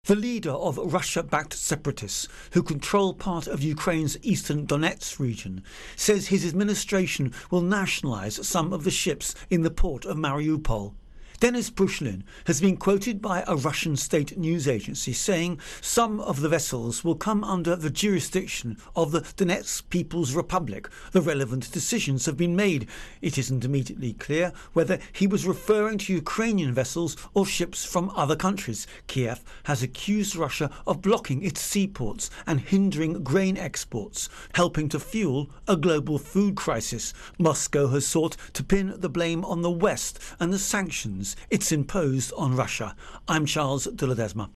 Russia Ukraine War Ships Intro and Voicer